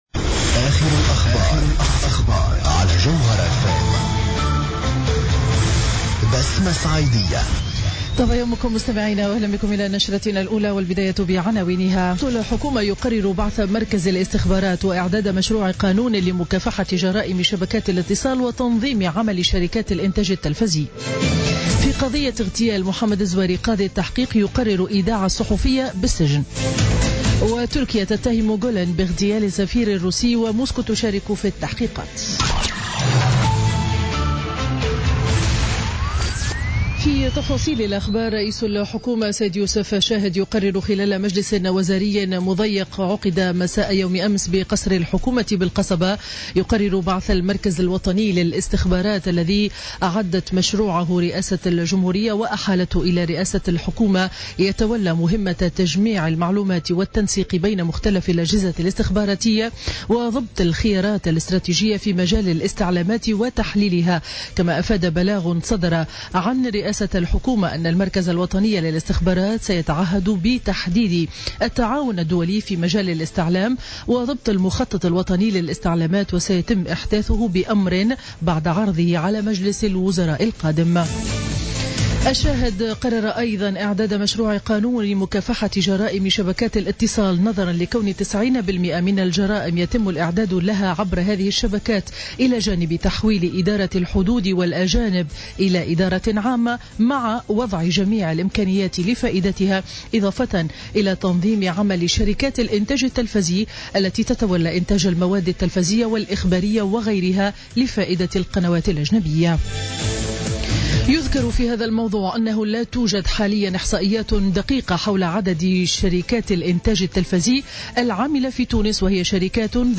نشرة أخبار السابعة صباحا ليوم الأربعاء 21 ديسمبر 2016